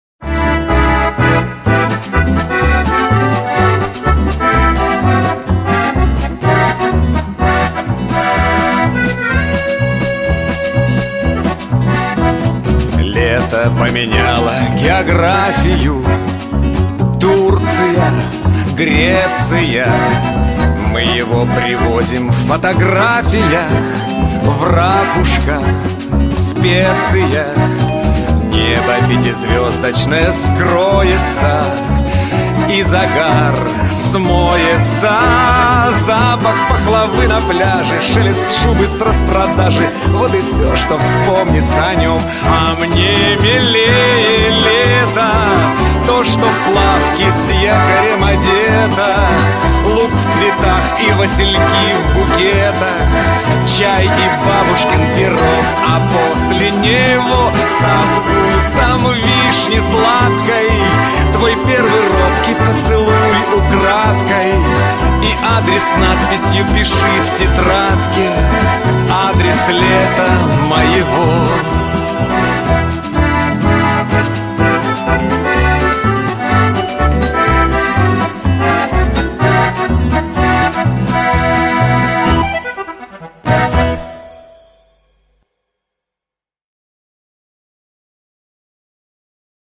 Мелодия заставки